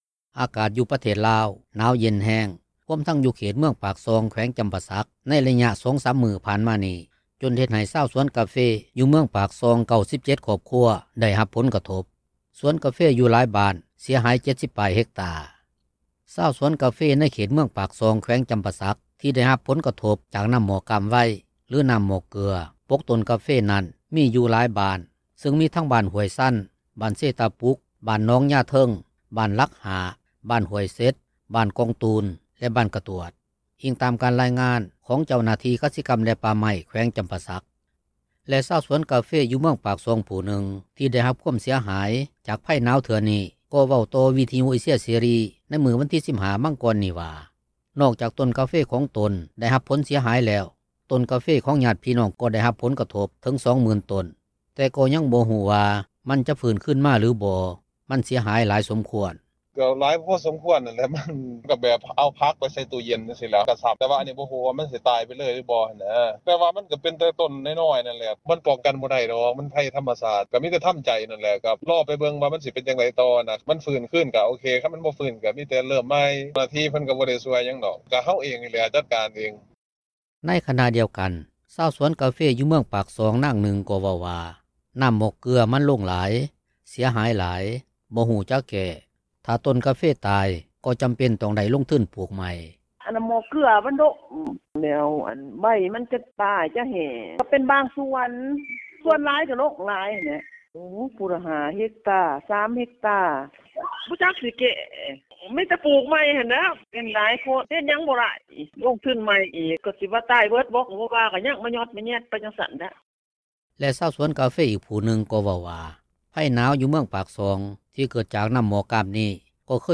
ໃນຂະນະດຽວກັນ, ຊາວສວນກາເຟ ຢູ່ເມືອງປາກຊ່ອງ ນາງໜຶ່ງ ກໍເວົ້າວ່າ ນໍ້າໝອກເກືອ ມັນລົງຫຼາຍ ເສຍຫາຍຫຼາຍ, ບໍ່ຮູ້ຈະແກ້, ຖ້າຕົ້ນກາເຟຕາຍ ກໍຈໍາເປັນ ຕ້ອງໄດ້ລົງທຶນປູກໃໝ່.